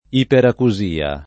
iperacusia
iperacusia [ iperaku @& a ] s. f. (med.)